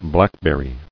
[black·ber·ry]